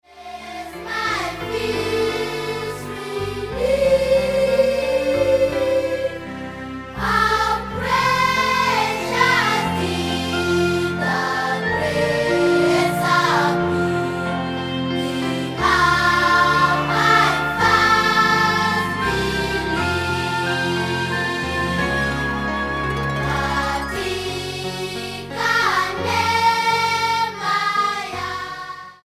STYLE: Childrens